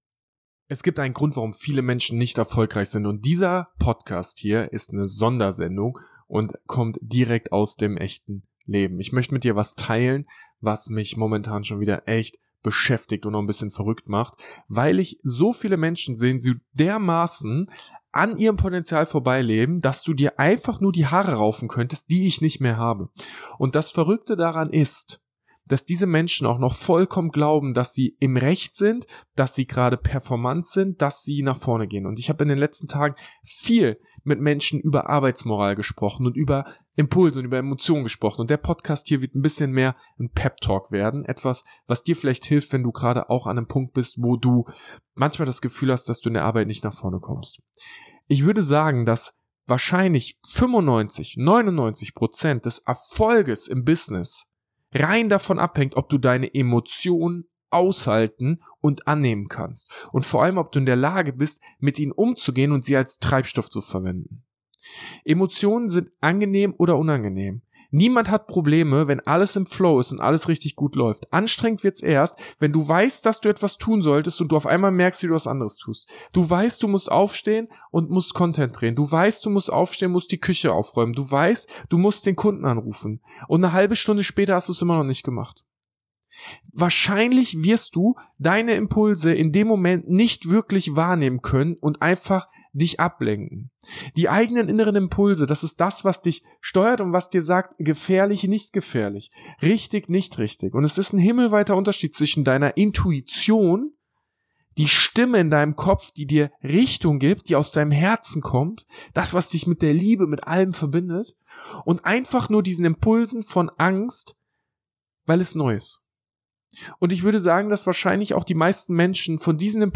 Motivational Speech | Folge 310 ~ Social Leadership Podcast